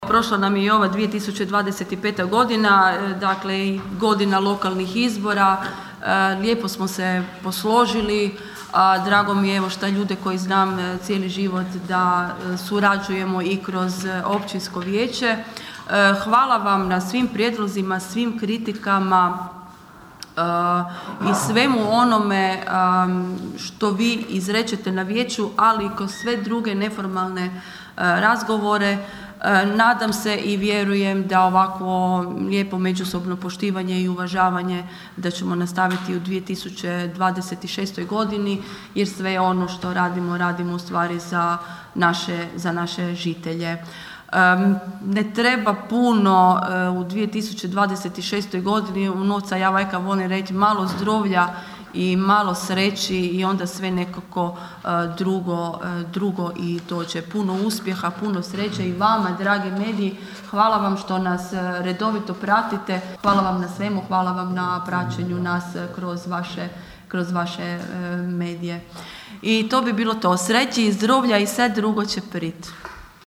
ton – Irene Franković).